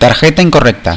card_verify_error.wav